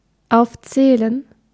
Ääntäminen
Synonyymit listen cunning enroll Ääntäminen US Tuntematon aksentti: IPA : /lɪst/ Haettu sana löytyi näillä lähdekielillä: englanti Käännös Konteksti Ääninäyte Substantiivit 1.